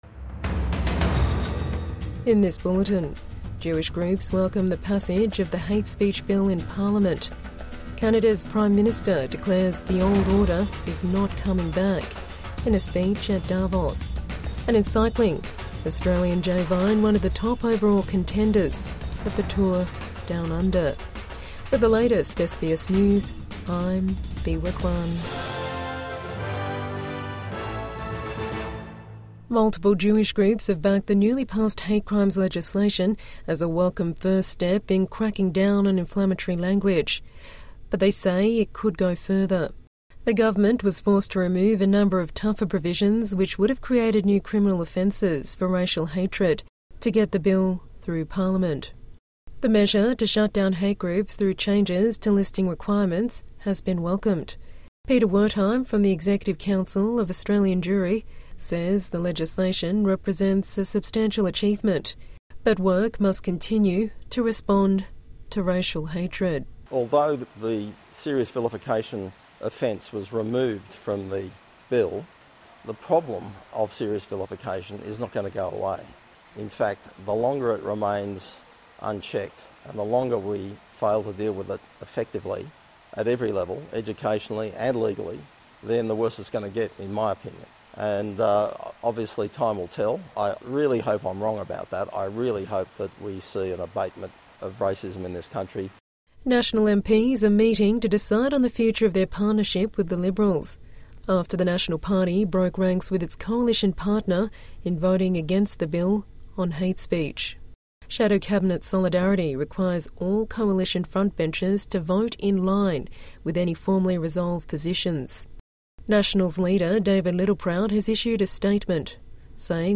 Jewish groups welcome passage of hate speech bill in parliament | Midday News Bulletin 21 January 2026